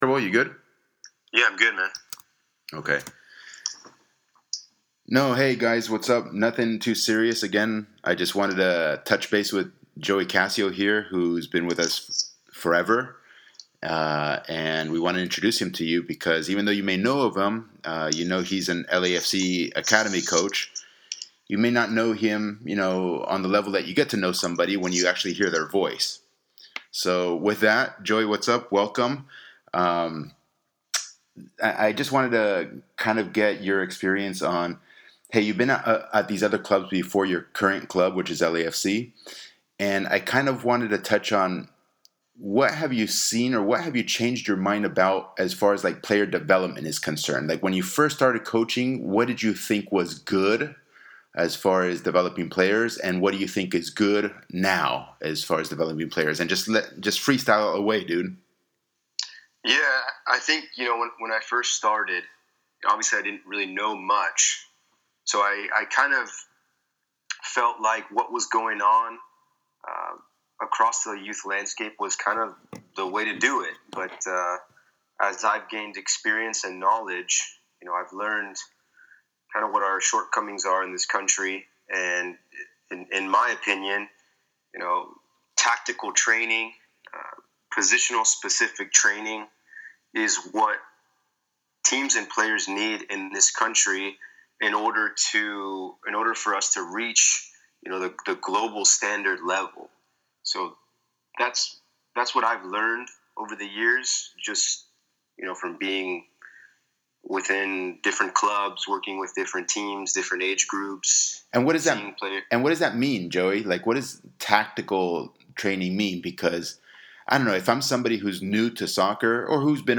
The following is a brief informal chat I had with him regarding his experience in youth tactical training.